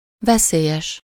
Ääntäminen
Vaihtoehtoiset kirjoitusmuodot (vanhahtava) redoubtable Ääntäminen France: IPA: /ʁə.du.tabl/ Haettu sana löytyi näillä lähdekielillä: ranska Käännös Ääninäyte Adjektiivit 1. veszélyes Suku: f .